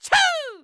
binf_attack_1c.wav